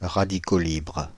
Ääntäminen
France (Île-de-France): IPA: [ʁa.di.ko libʁ]